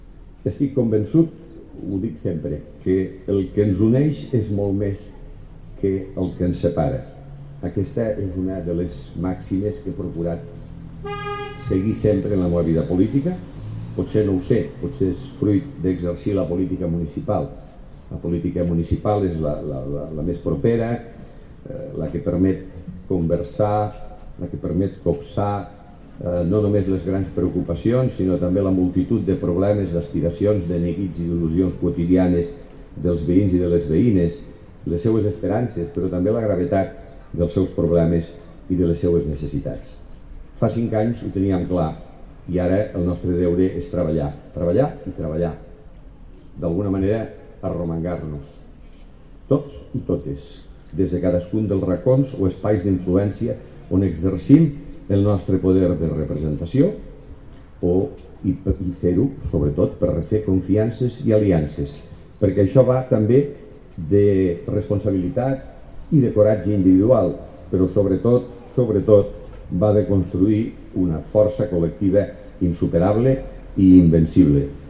L’alcalde de Lleida, Miquel Pueyo, ha presidit l’acte institucional organitzat per la Paeria amb motiu del 5è aniversari de l’1 d’octubre. L’acte s’ha fet a la plaça de l’U d’Octubre al barri de Cappont, un dels escenaris protagonistes a la ciutat durant aquella jornada.
tall-de-veu-de-lalcalde-miquel-pueyo-sobre-lacte-institucional-per-commemorar-els-5-anys-de-l1-doctubre